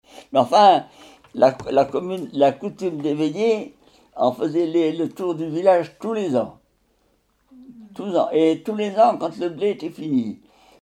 Témoignage